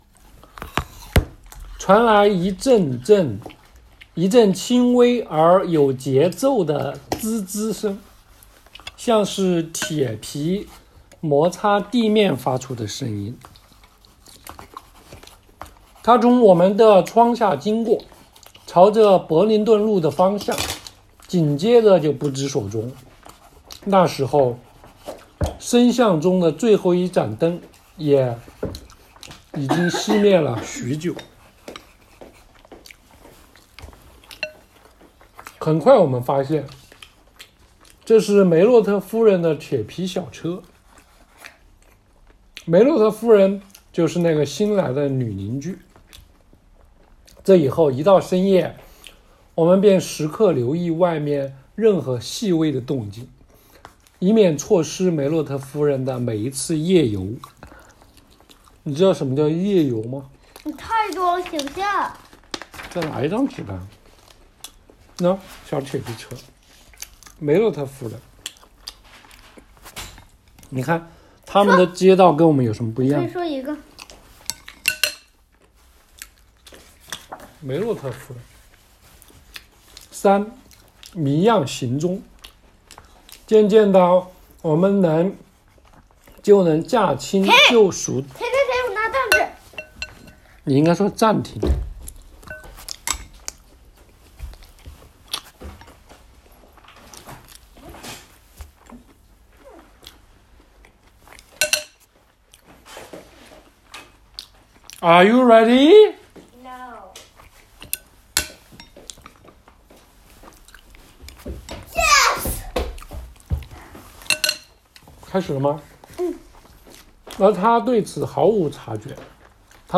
一部讲述法国和法国电影的书，写的晦涩，但是读出来感觉却还不错